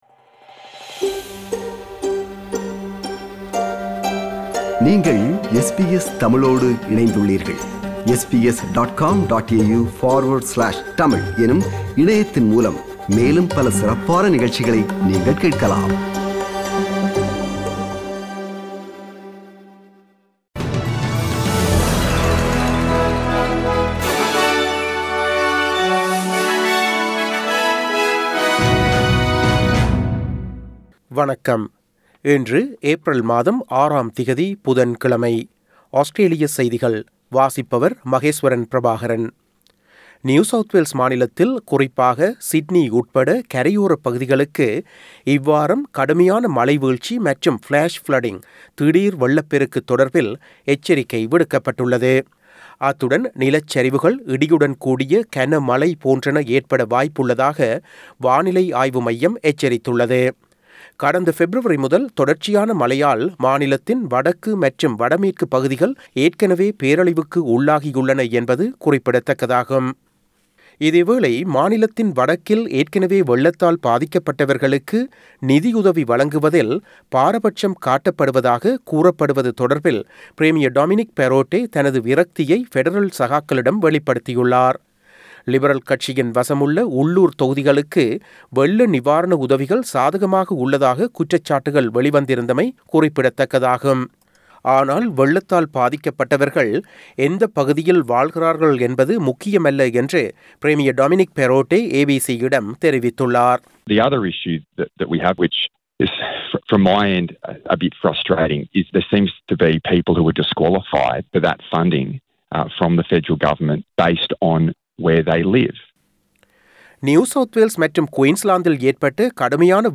Australian news bulletin for Wednesday 06 April 2022.